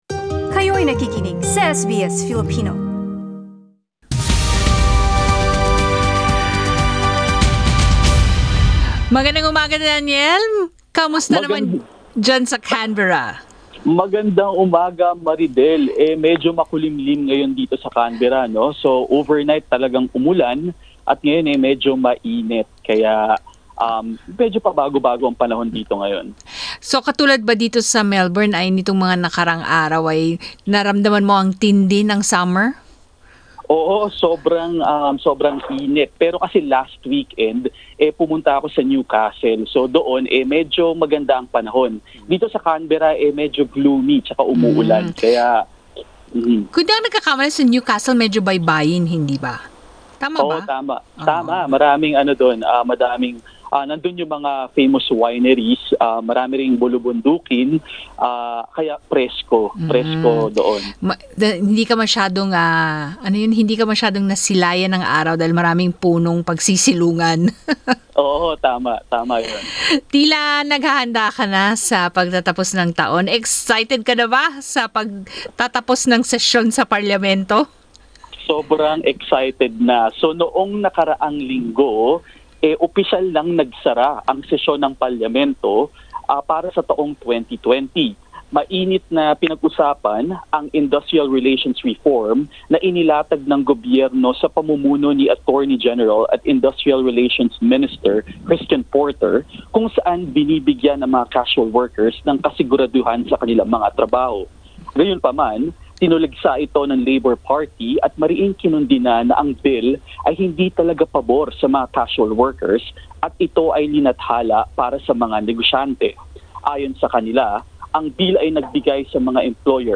Filipinos in Canberra celebrate first of nine services for the Dawn Mass.
Pakistani Community joined the mass and sang local Urdu Christmas songs while the St Michael Choir filled the church with Filipino Christmas songs Source